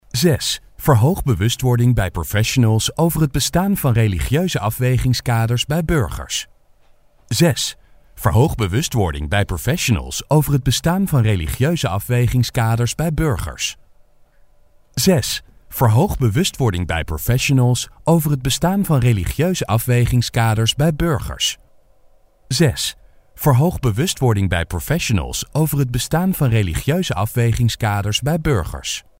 荷兰语中年大气浑厚磁性 、沉稳 、娓娓道来 、男专题片 、宣传片 、纪录片 、广告 、课件PPT 、工程介绍 、绘本故事 、动漫动画游戏影视 、400元/百单词男荷01 荷兰语男声 大气 大气浑厚磁性|沉稳|娓娓道来
男荷01 荷兰语男声 课件 干音 大气浑厚磁性|沉稳|娓娓道来